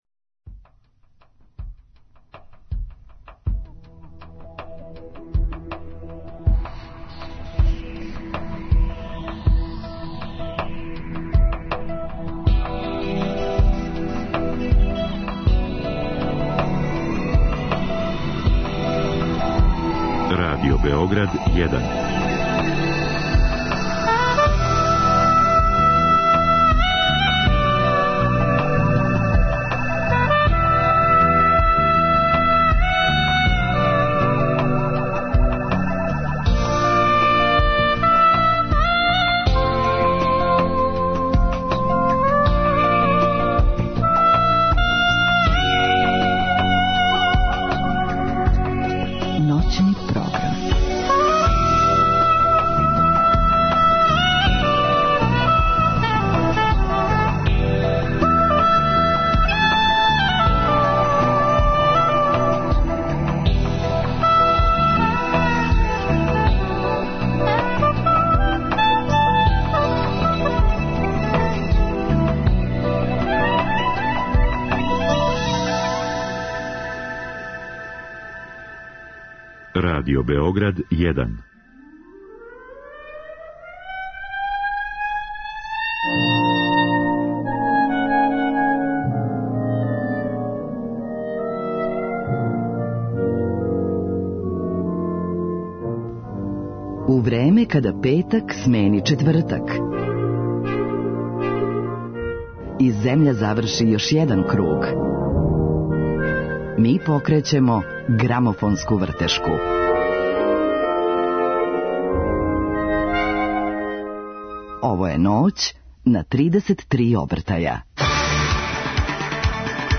Гост Ноћи на 33 обртаја биће певач и гитариста група 'Џентлмени' и 'Дах' Бранко Марушић Чутура. Разговараћемо о снимању плоча, игранкама, наcтупима, али и о његовим афоризмима и хобијима.